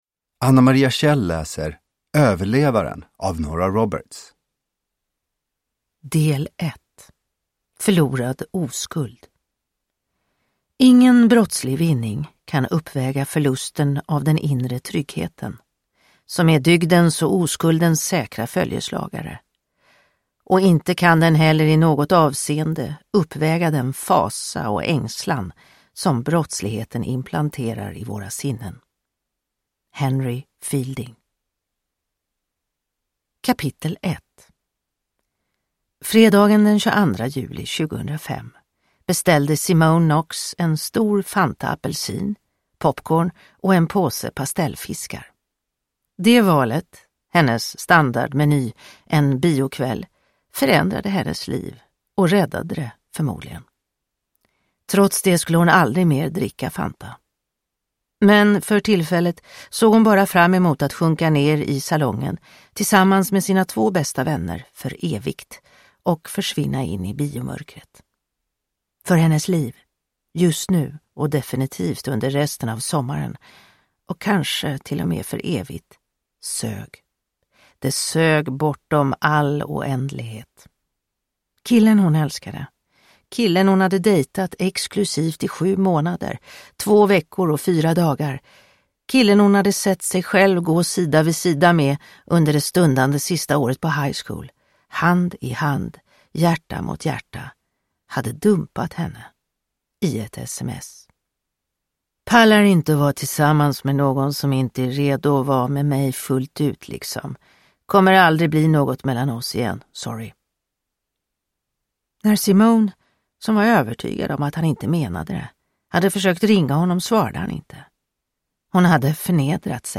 Överlevaren – Ljudbok – Laddas ner